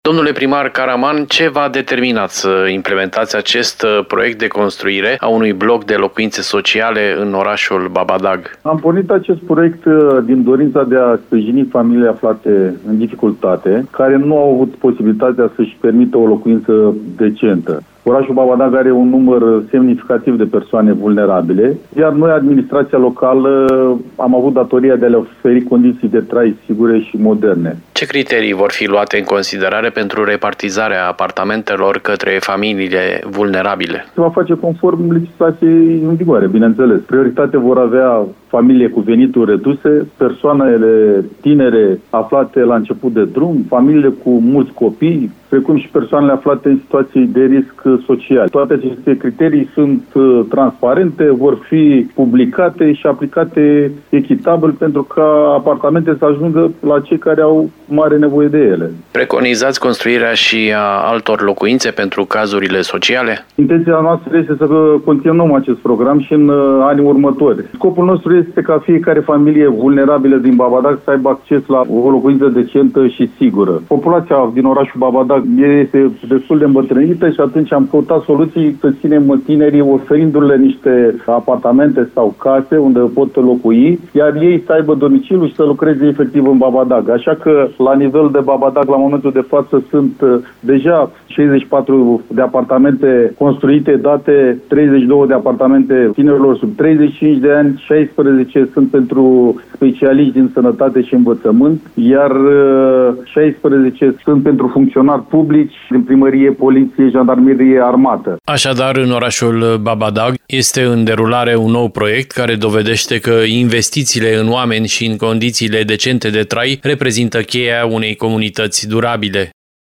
din discuția cu primarul Georgian Caraman.